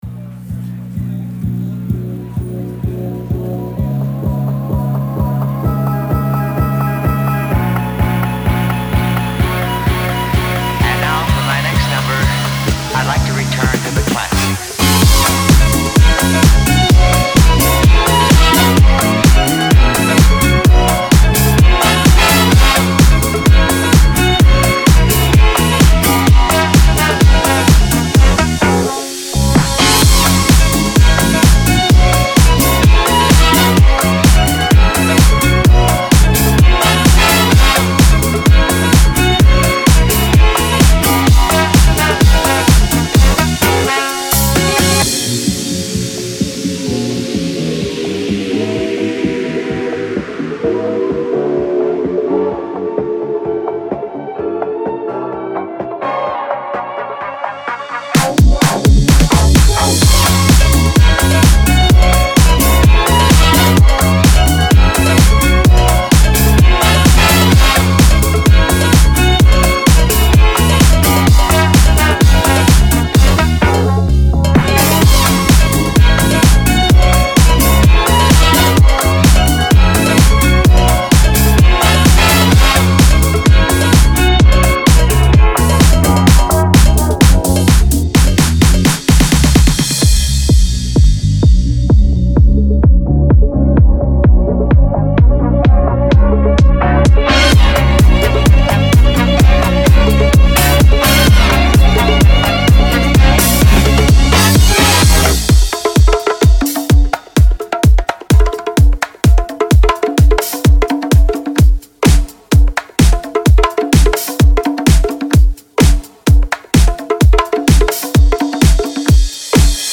Future Funk